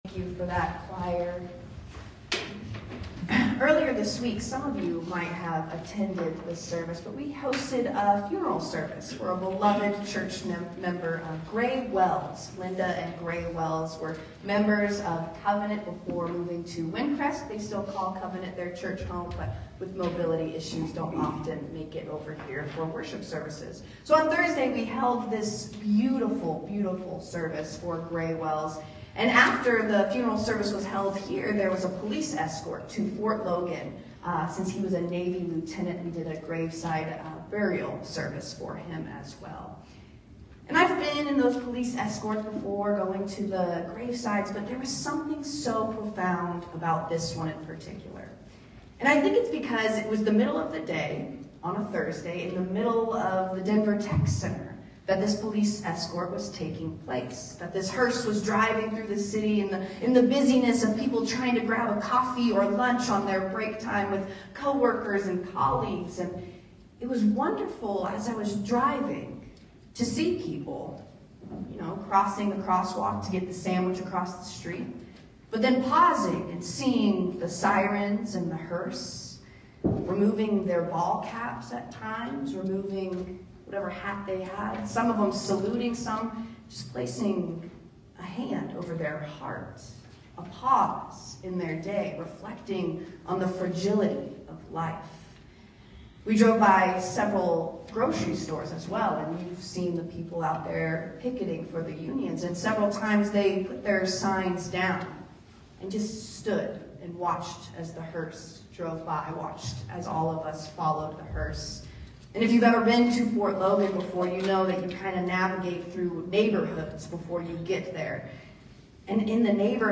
Sermons | Covenant Presbyterian DTC